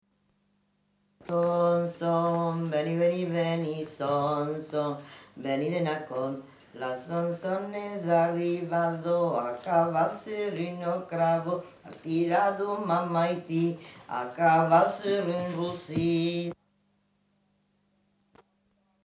Aire culturelle : Lauragais
Genre : chant
Effectif : 1
Type de voix : voix de femme
Production du son : chanté
Classification : som-soms, nénies